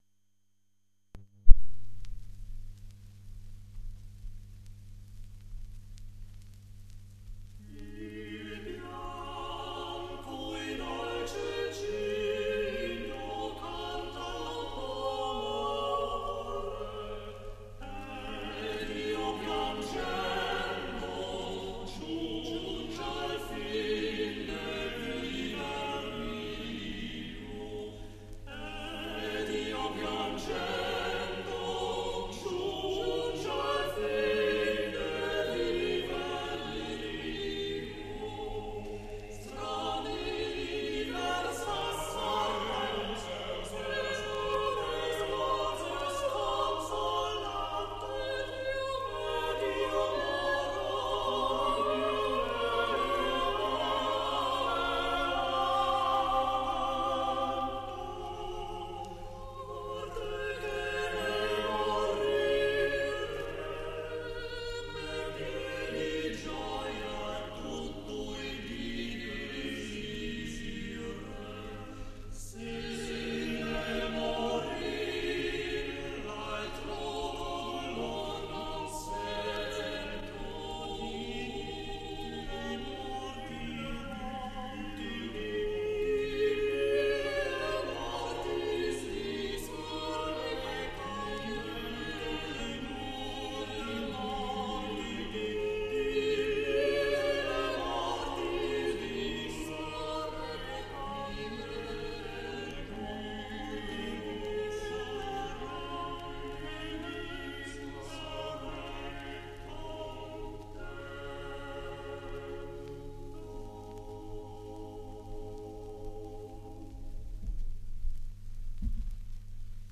Arcadelt, Il bianco e dolce cigno (madrigale).mp3